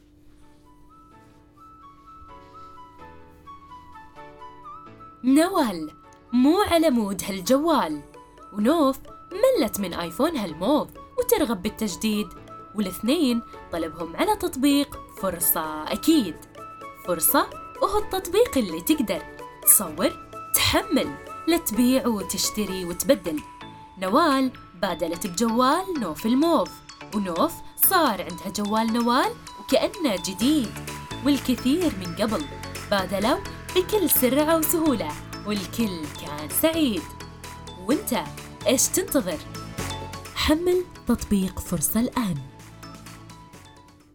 دوري هو الترويج لمنصة بيع افتراضية ذكرت مميزات هذه المنصة و هذا الاعلان تم تسجيله باللهجة الكويتية وهذه المنصة اسمها فرصة وتستهدف الجمهور الكويتي
اعلان لفيديو تشويقي – اللهجة الكويتية